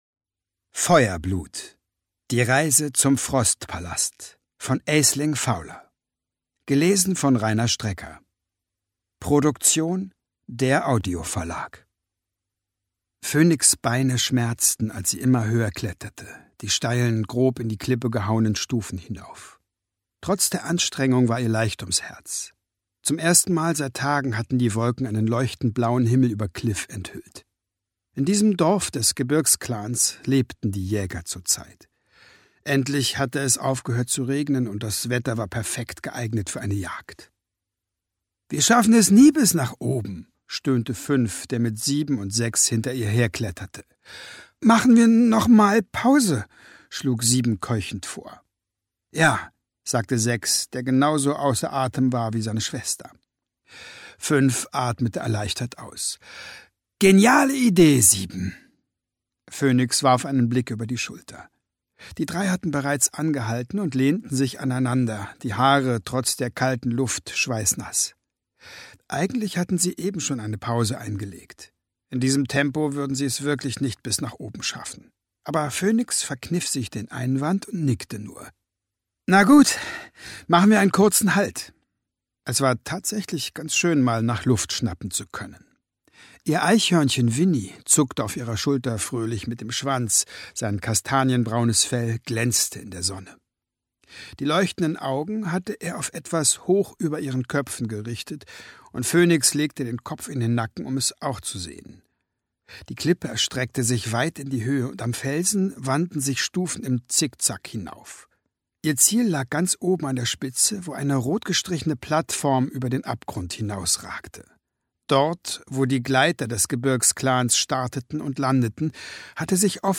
Ungekürzte Lesung mit Rainer Strecker (1 mp3-CD)
Rainer Strecker (Sprecher)
»Große Sprecher-Kunst.«